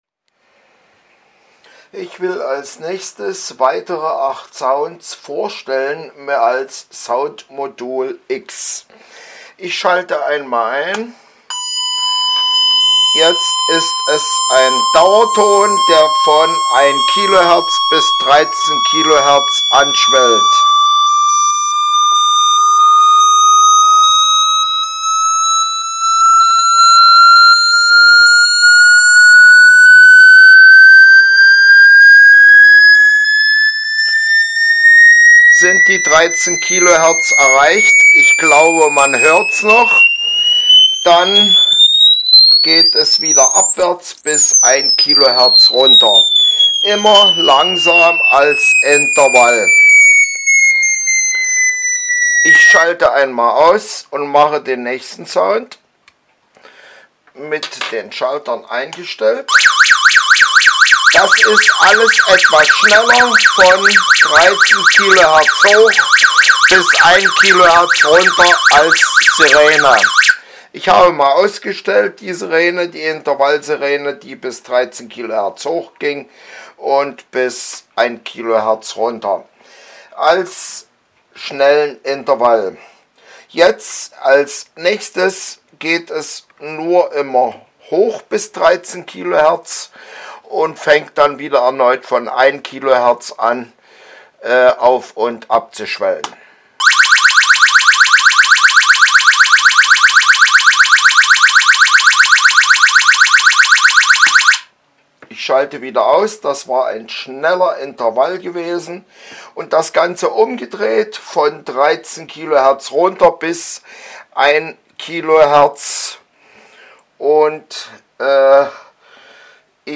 1- Langsamer Intervall von 1Khz hoch bis 13Khz
2- Schneller Intervall von 1Khz hoch bis 13Khz
3- Schneller Intervall von 1Khz bis 13Khz hoch
4- Schneller Intervall von 13Khz bis 1Khz runter
5- Ein Spatz pfeift dann 2sec Pause
6- 2 mal zwitschern dann 2sec Pause
7- Ein Pfiff dann 2sec Pause
8- Zwei Pfiffe dann 2sec Pause
obigen Sound-X-Möglichkeiten vorgeführt.